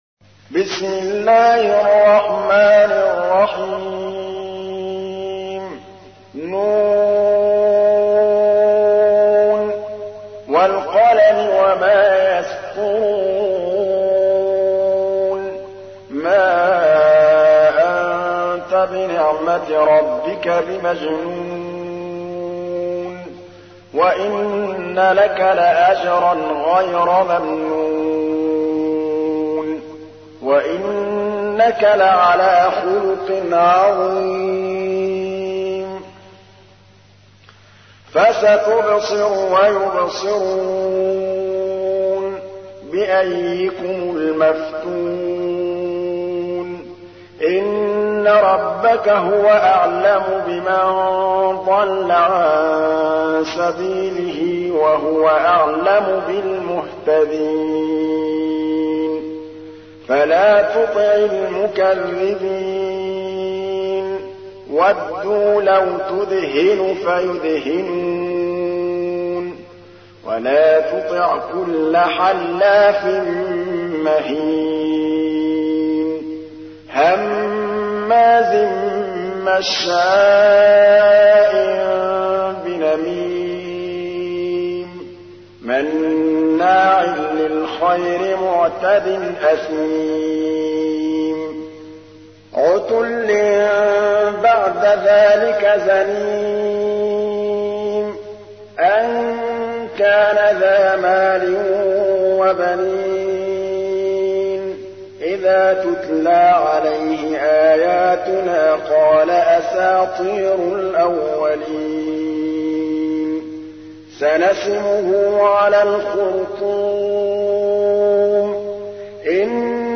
تحميل : 68. سورة القلم / القارئ محمود الطبلاوي / القرآن الكريم / موقع يا حسين